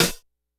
Perc_131.wav